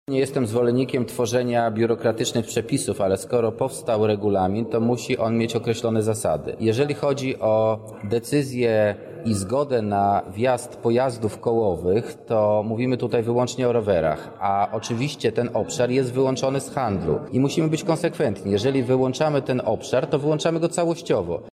O regulaminie placu Litewskiego mówi radny Marcin Nowak: